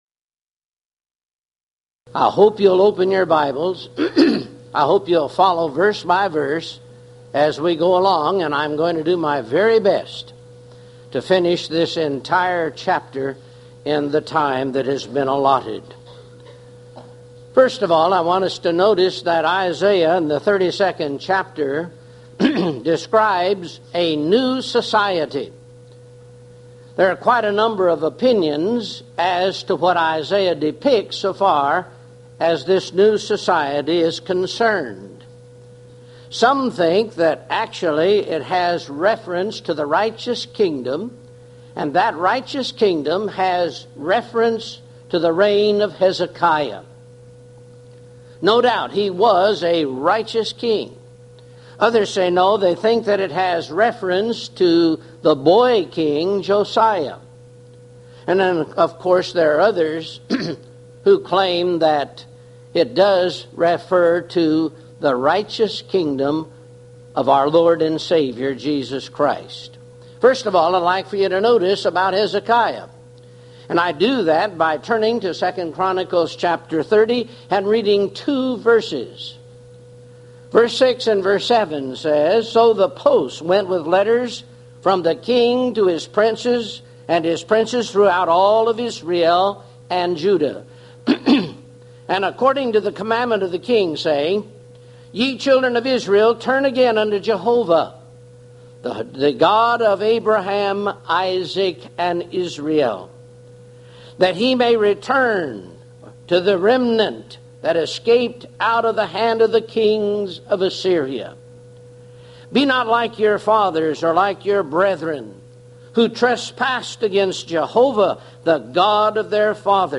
Series: Houston College of the Bible Lectures Event: 1995 HCB Lectures Theme/Title: The Book Of Isaiah - Part I